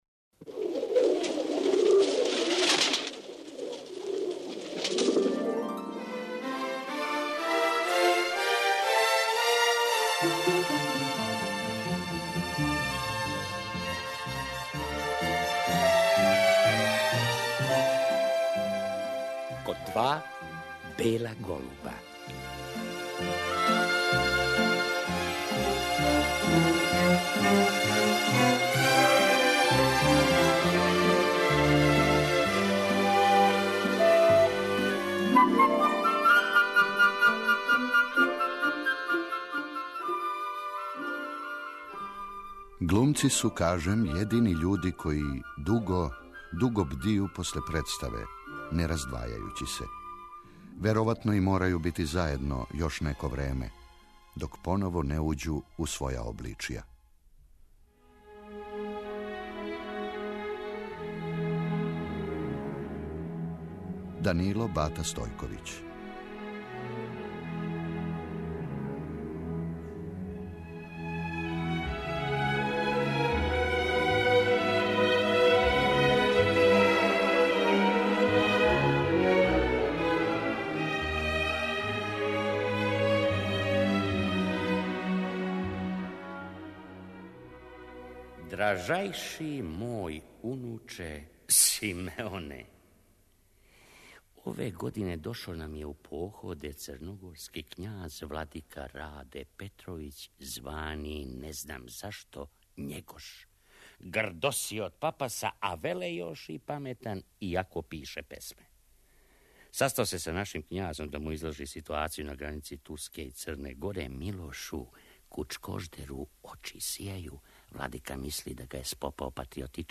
Чућемо и снимак са доделе Добричиног прстена Бати Стојковићу 1990. године и одломке из радио адаптације "Корешподенције" Борислава Пекића.